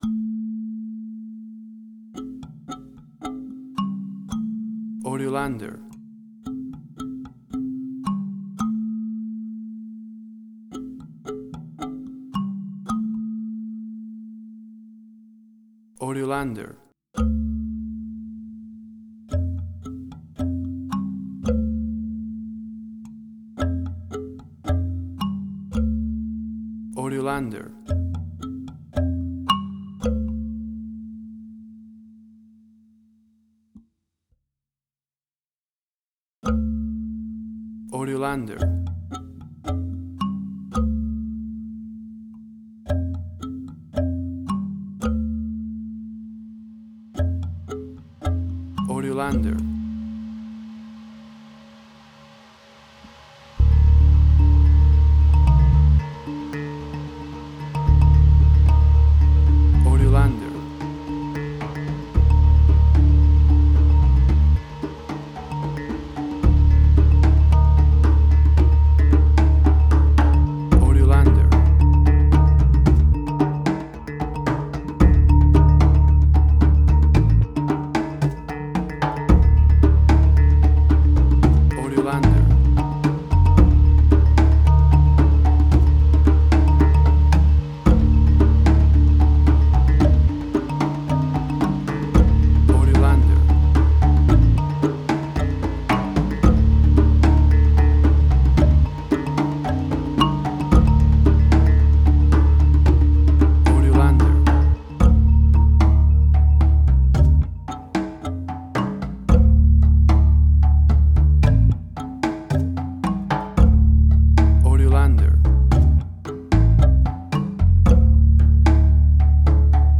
World Fusion_Similar_BBC Documentaries.
WAV Sample Rate: 16-Bit stereo, 44.1 kHz
Tempo (BPM): 112